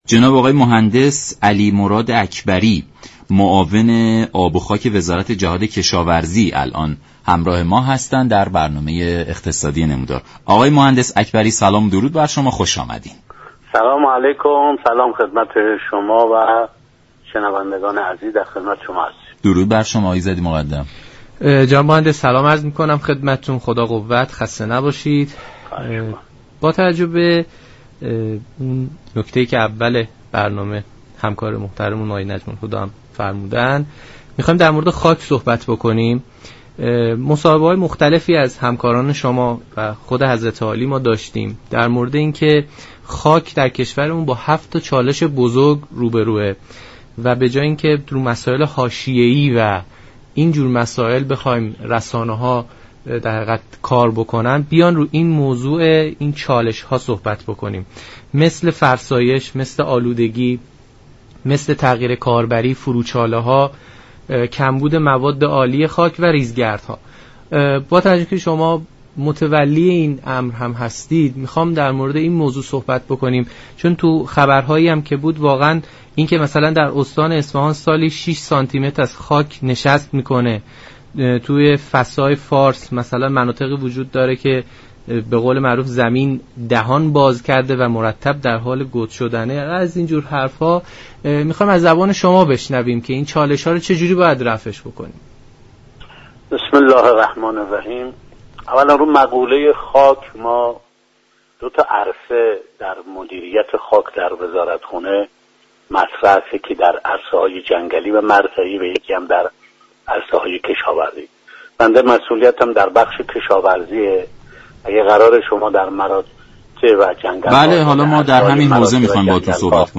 به گزارش شبكه رادیویی ایران، علیمراد اكبری معاون آب و خاك وزارت جهاد كشاورزی در برنامه نمودار به وضعیت خاك كشاورزی ایران پرداخت و گفت: خاك كشاورزی ایران فرسایش چندان زیادی ندارد و طی سال های گذشته 2 میلیون هكتار از زمین های آبی مجهز به سیستم آبیاری مدرن شده اند.